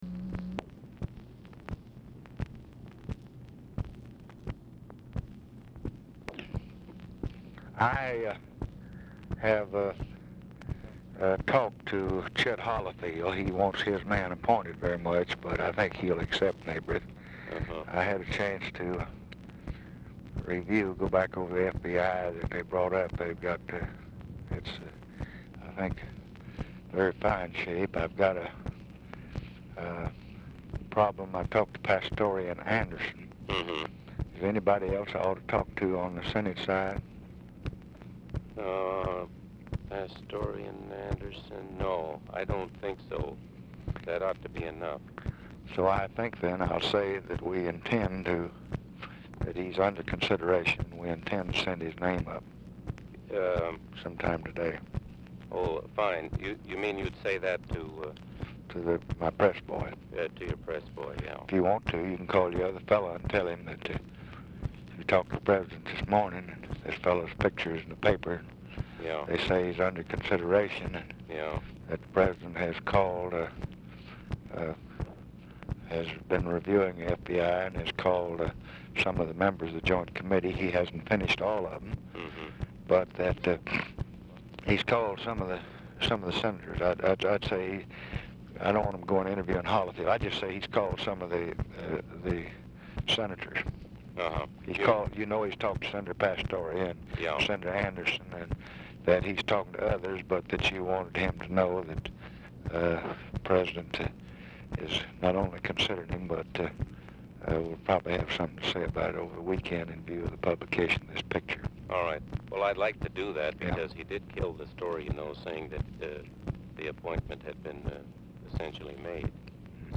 Telephone conversation # 10245, sound recording, LBJ and GLENN SEABORG, 6/18/1966, 10:45AM | Discover LBJ
RECORDING STARTS AFTER CONVERSATION HAS BEGUN
Format Dictation belt
Location Of Speaker 1 Oval Office or unknown location
Specific Item Type Telephone conversation